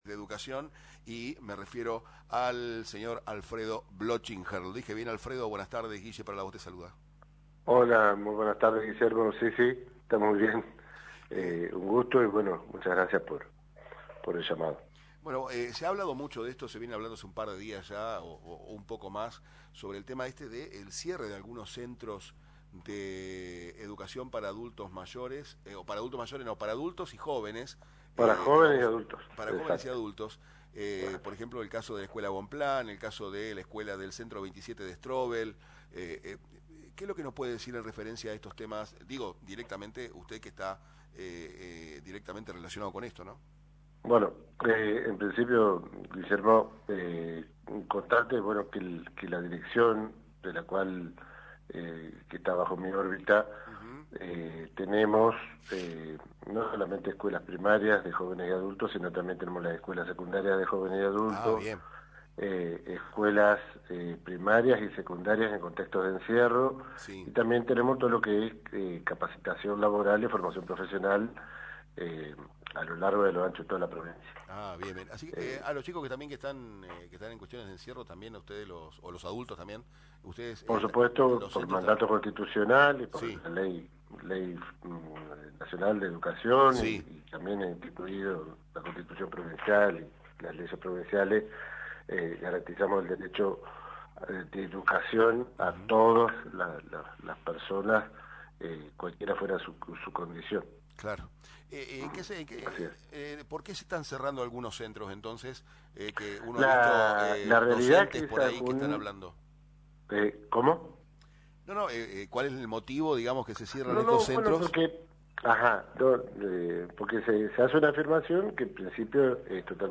Alfredo Blochinger, director de Jóvenes y Adultos del Consejo General de Educación, en comunicación telefónica con RADIO LA VOZ, se refirió al destino de los Centros de Capacitación Educativa para Jóvenes y Adultos en la provincia.